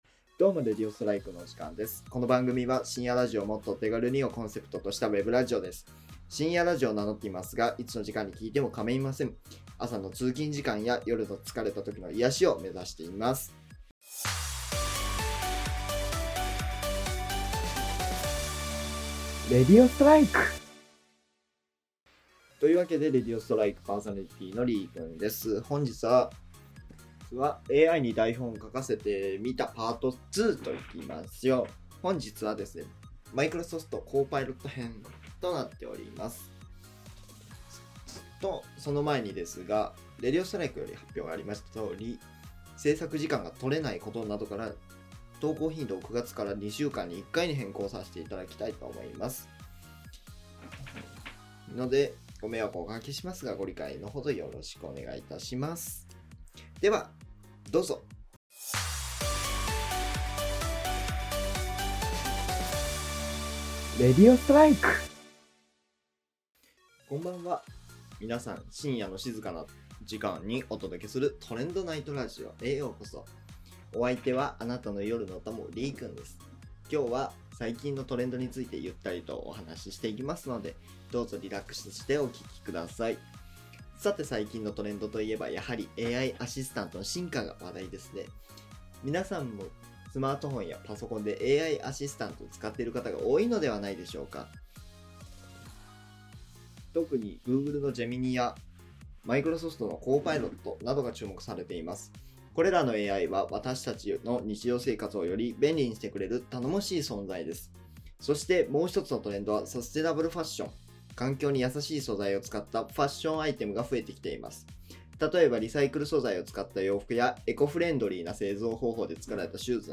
深夜ラジオっぽいおとなしさ